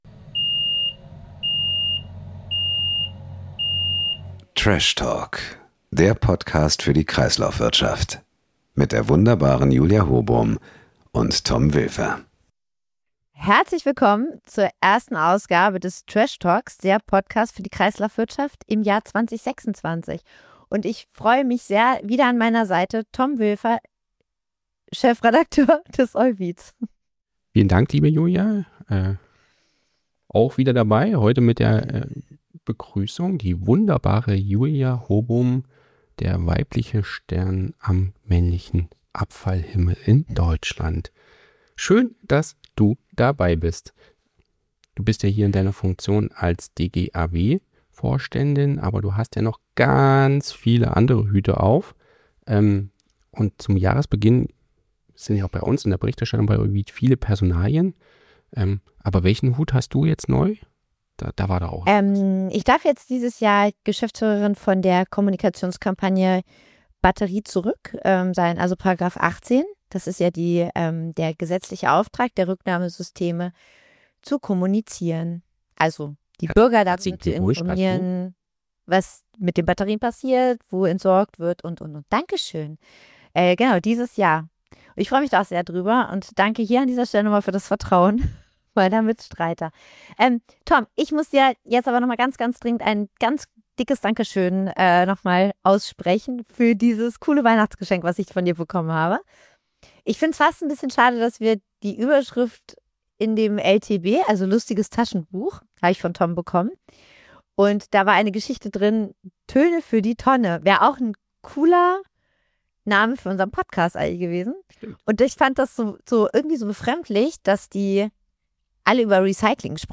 Einer musikalischen!